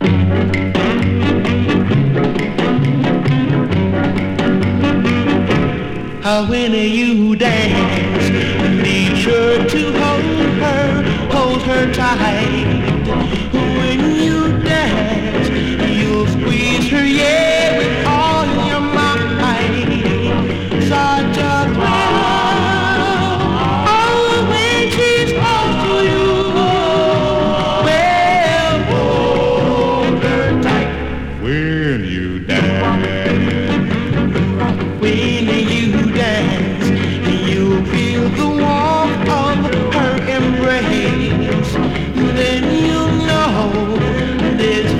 Rock’N’Roll, Doo Wop　USA　12inchレコード　33rpm　Mono